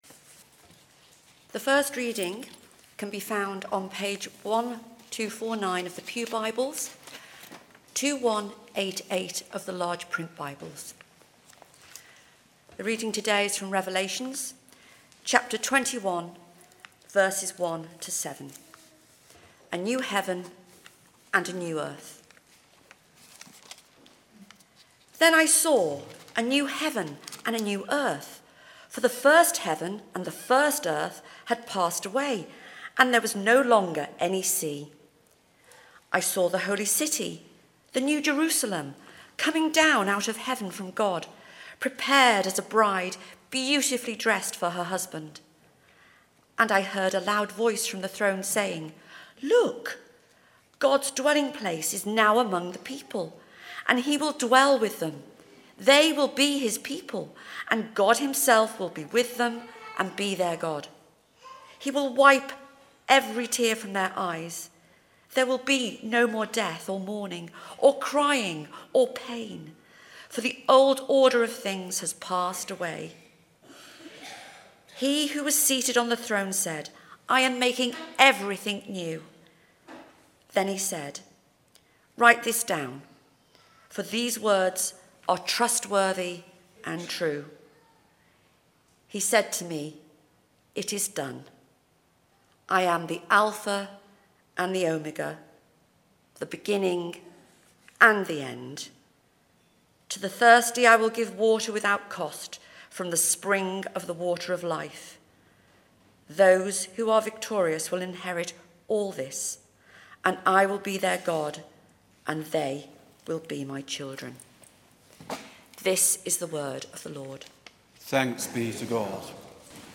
Theme: Thanksgiving Service for the life of HM Queen Elizabeth II Sermon